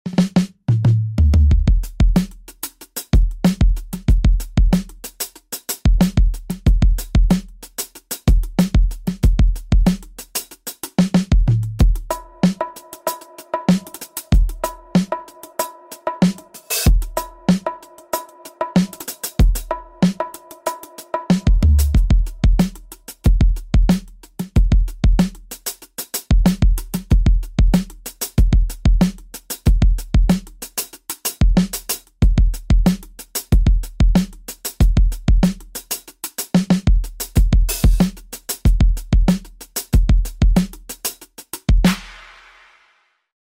Dry Drum Groove on a sound effects free download
Dry Drum Groove on a Roland Vad 706 Kit